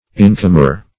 Search Result for " incomer" : The Collaborative International Dictionary of English v.0.48: Incomer \In"com`er\, n. 1.